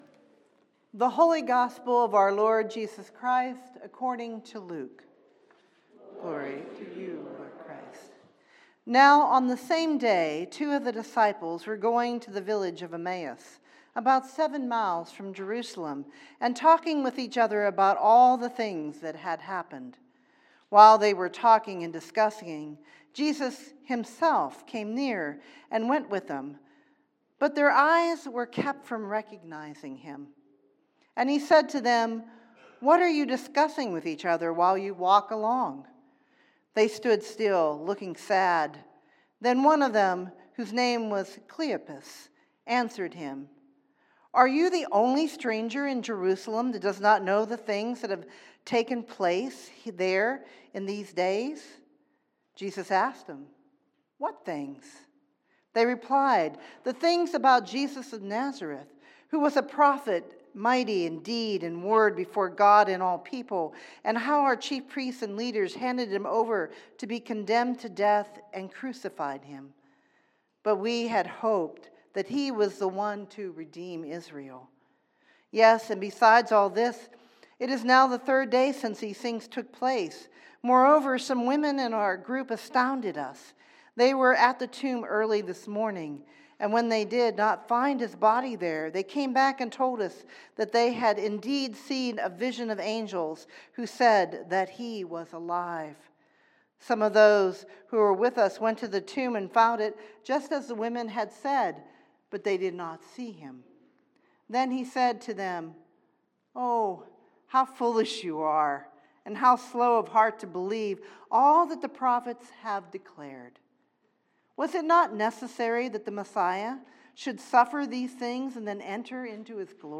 Third Sunday in Easter